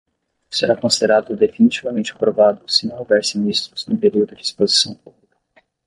Pronounced as (IPA)
/a.pɾoˈva.du/